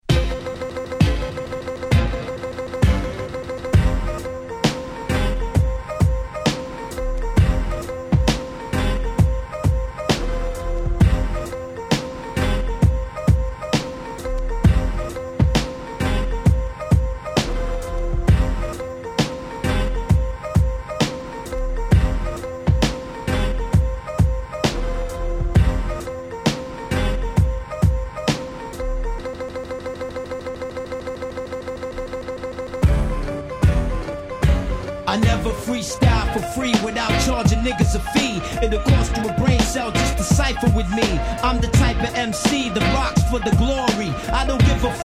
98' Smash Hit Hip Hop !!
和風の遅いBeatがだんだん速くなっていくIntroは当時首を振りすぎてもげそうになった記憶しかありません！
90's Boom Bap ブーンバップ